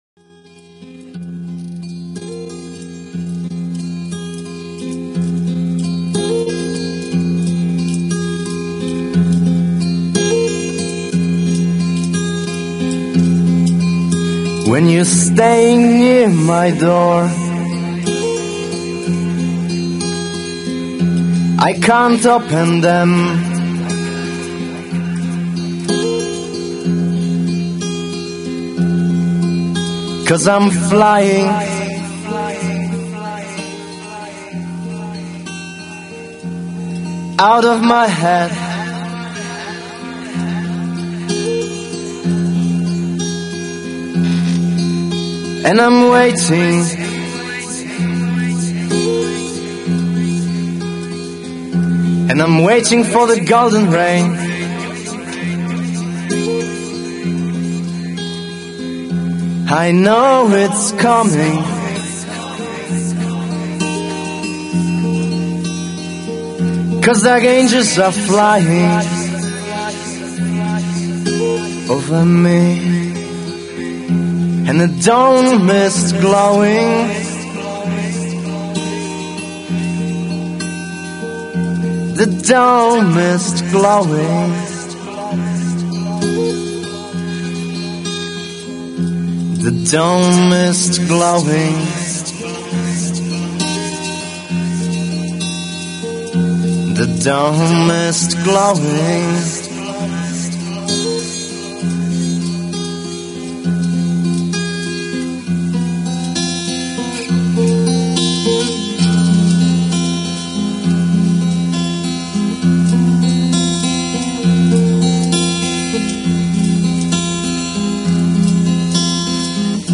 demo/live